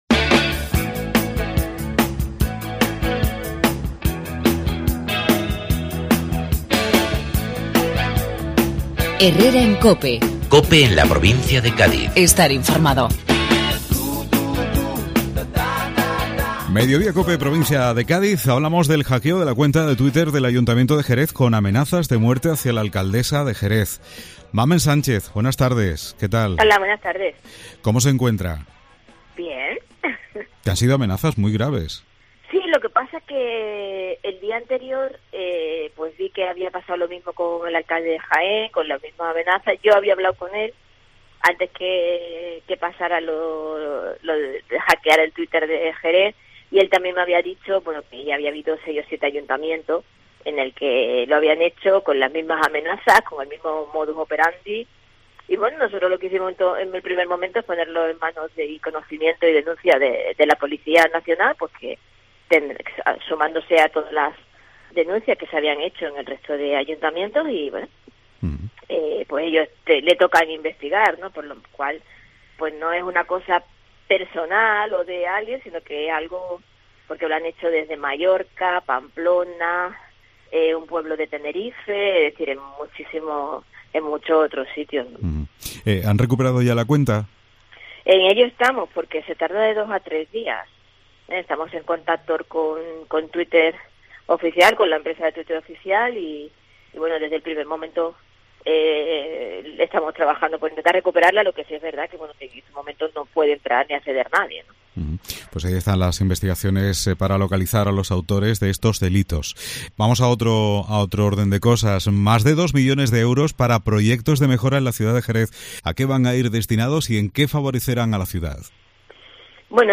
Entrevista a Mamen Sánchez, alcaldesa de Jerez